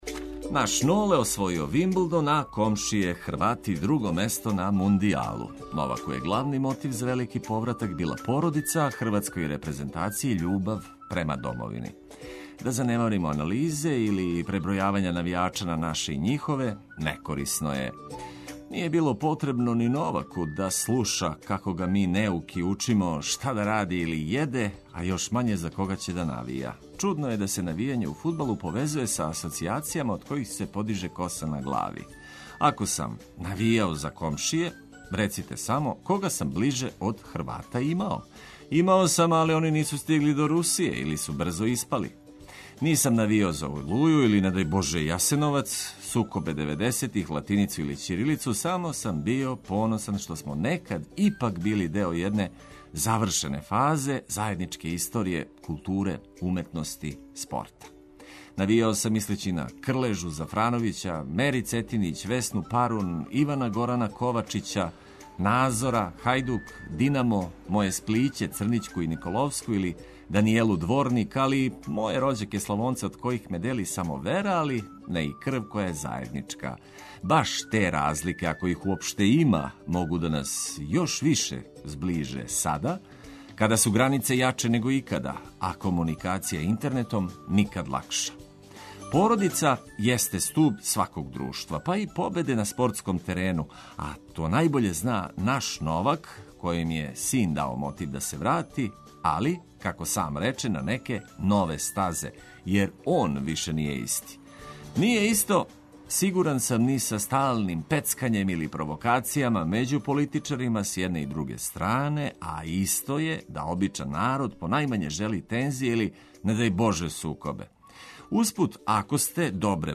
Сјајно расположење, корисне информације и величанствени хитови су ту.